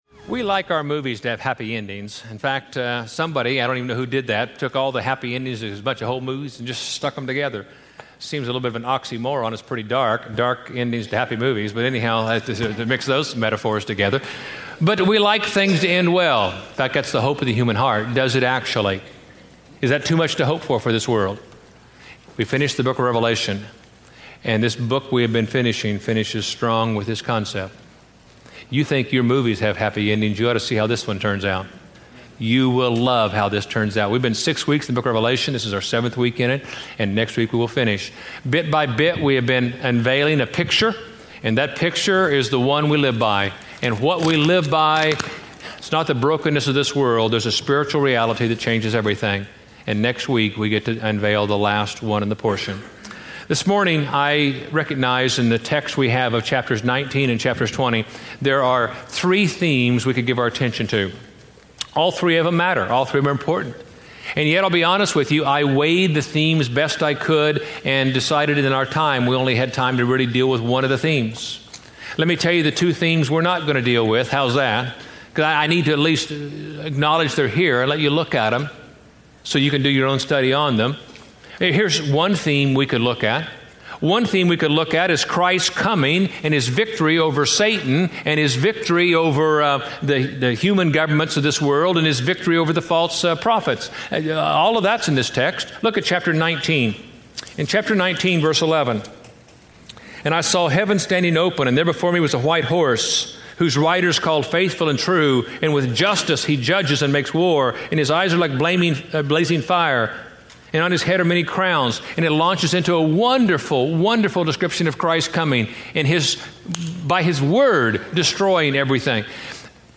Preached at College Heights Christian Church May 27, 2007 Series: Living at Peace in a World Falling to Pieces Scripture: Revelation 19-21 Audio